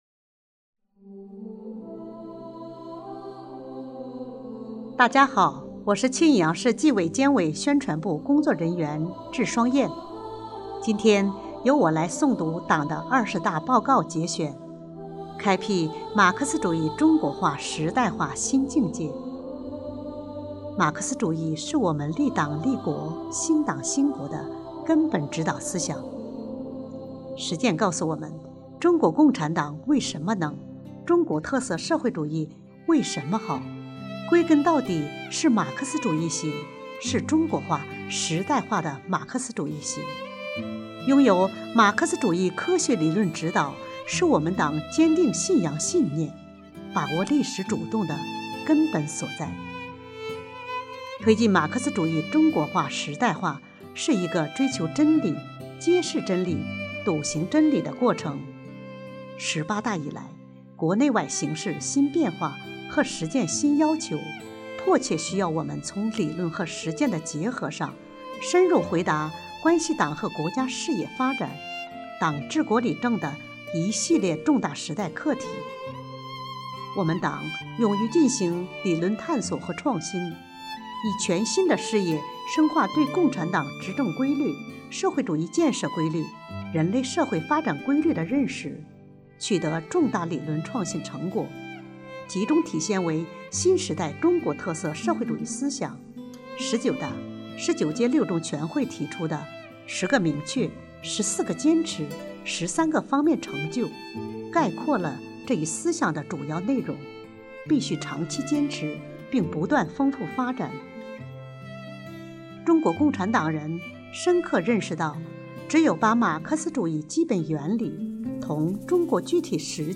本期诵读人